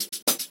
Closed Hats
few_hatz.wav